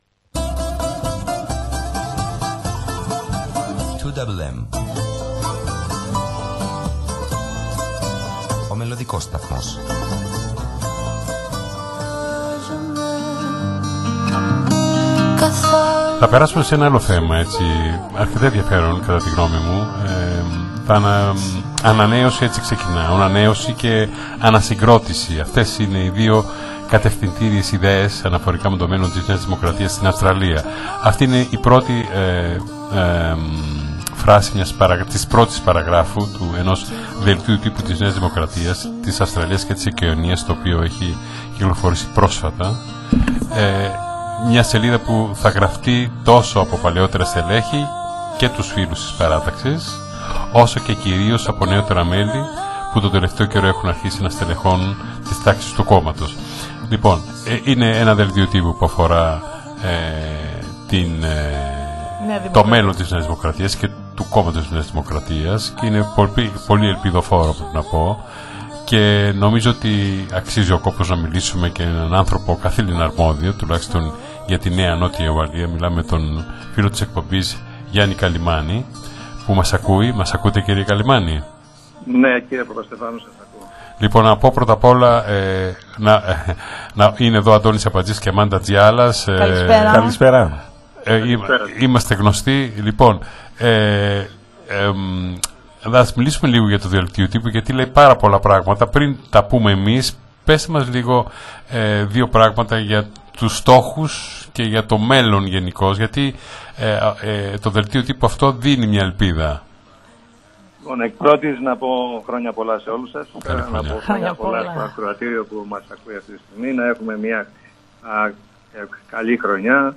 στον ελληνόφωνο ραδιοσταθμό του Σίνδνει